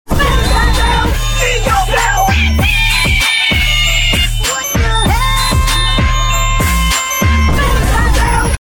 Vine Boom (funny)